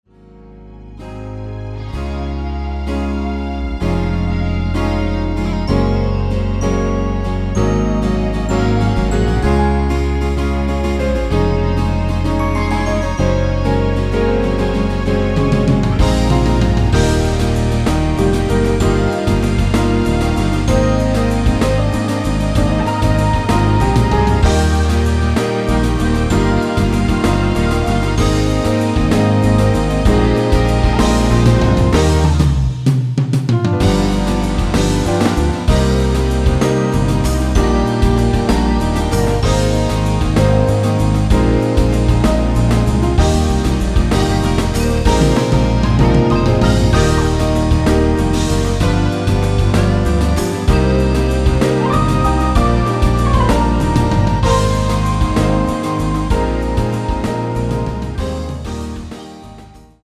◈ 곡명 옆 (-1)은 반음 내림, (+1)은 반음 올림 입니다.
앞부분30초, 뒷부분30초씩 편집해서 올려 드리고 있습니다.
중간에 음이 끈어지고 다시 나오는 이유는
위처럼 미리듣기를 만들어서 그렇습니다.